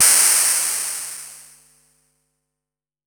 Modular Cymbal.wav